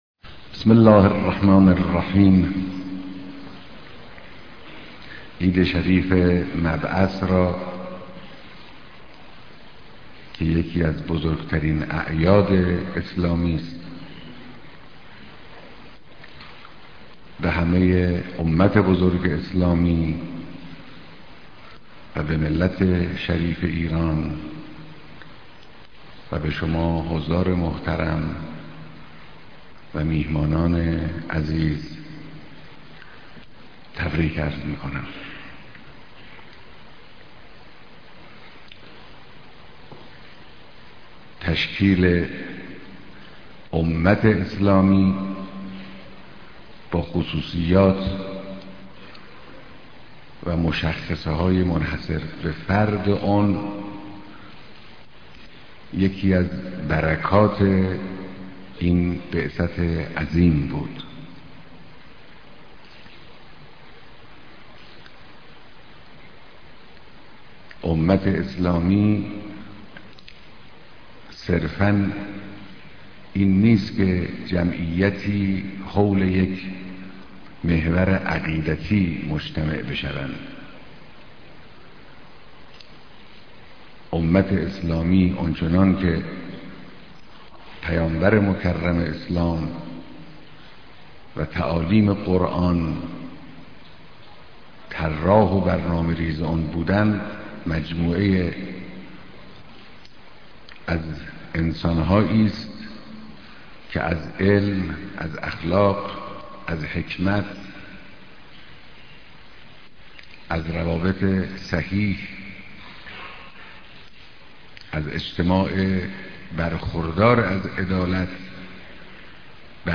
بيانات در ديدار مسؤولان و كارگزاران نظام بهمناسبت مبعث فرخندهى پيامبر مكرم اسلام